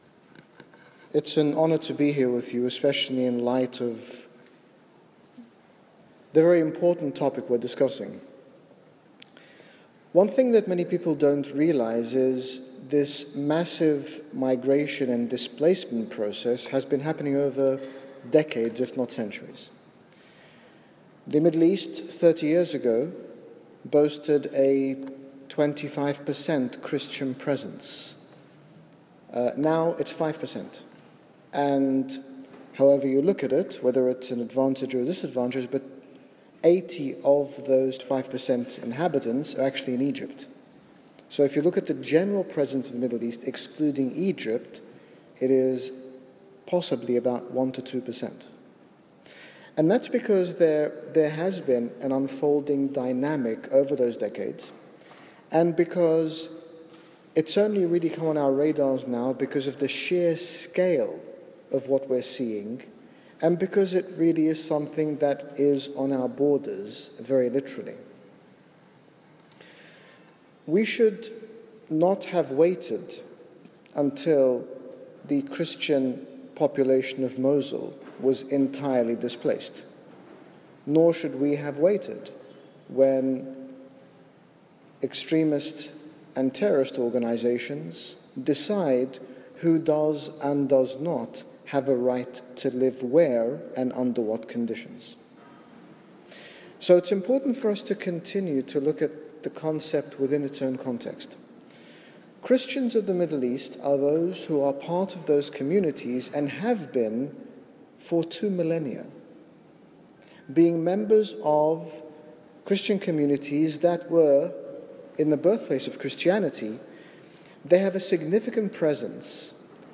Address by His Grace Bishop Angaelos, Genera Bishop of the Coptic Orthodox Church in the United Kingdom regarding refugees and displaced peoples during an Advent Vigil for Refugees at St Margaret's Church, Westminster Abbey.
Refugee Vigil Westminster.mp3